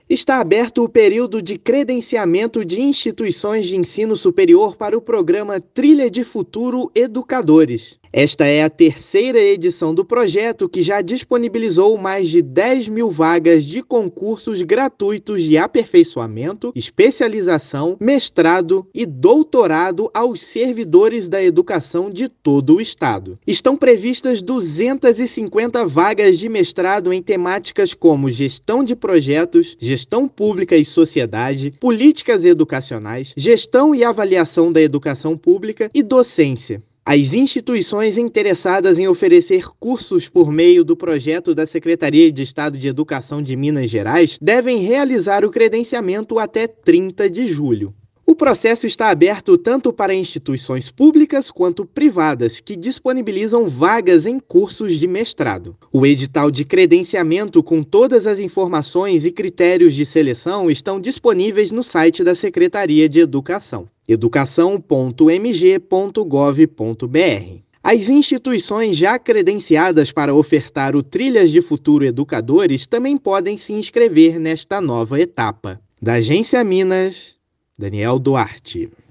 Inscrições começam nesta terça-feira (25/6) e vão até o dia 30/7. Ouça a matéria de rádio: